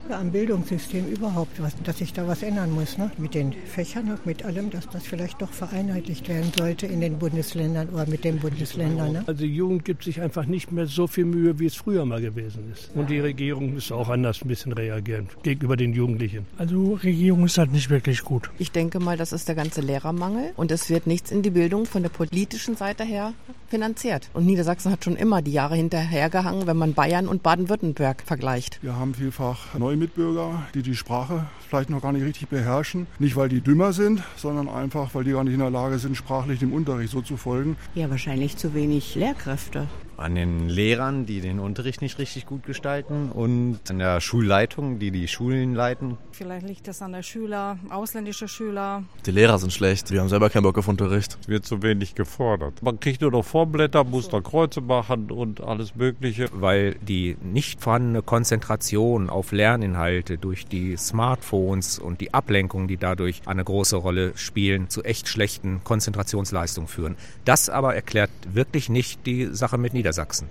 Umfrage zur Bildungsstudie